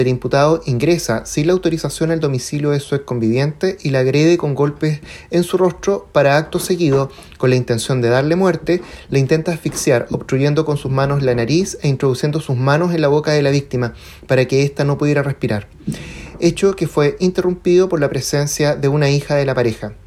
El fiscal (s) Felipe Horn relató en la audiencia de formalización de cargos que el acusado golpeó y posteriormente intentó asfixiar a su exconviviente.
ds-fiscal.mp3